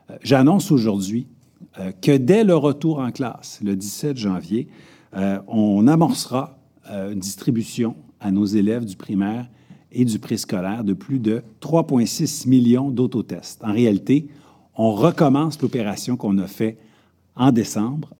Le ministre de l’Éducation, Jean-François Roberge, a précisé en conférence de presse que de nombreuses mesures seraient mises en place pour s’assurer d’un retour en classe sécuritaire pour les élèves et les enseignants.